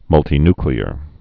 (mŭltē-nklē-ər, -ny-, -tī-)